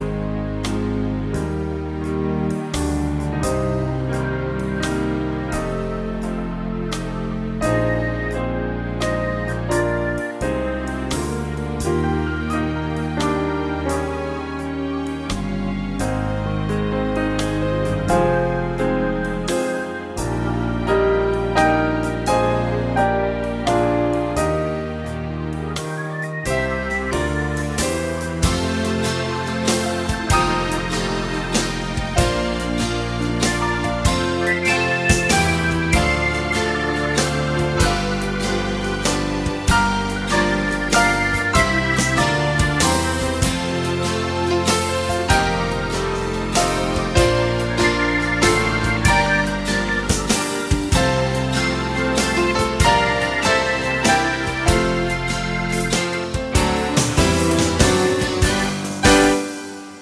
Musicals
Ein Musical für Kids & Teens